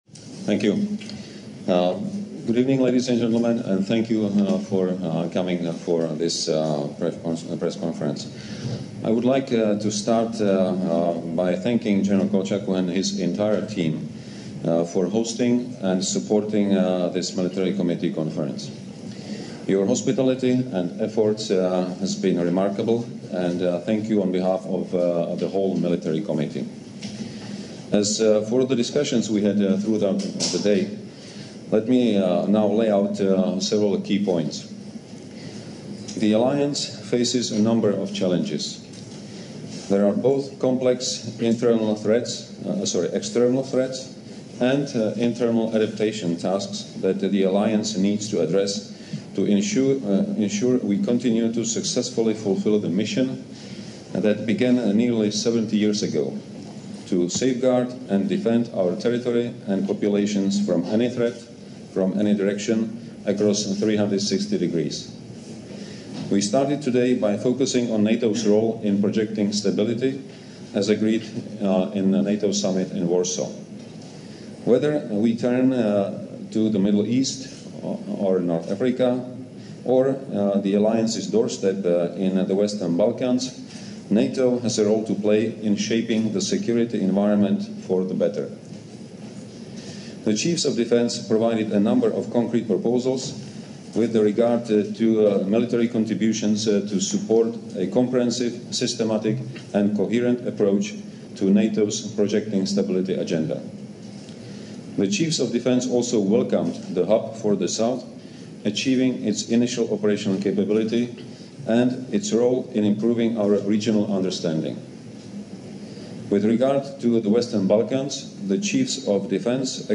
Military Committee Conference in Chiefs of Defence Session - Tirana, Albania
Opening Remarks by the Chairman of the NATO Military Committee, General Petr Pavel and the Prime Minister of Albania, His Excellency Mr. Edi Rama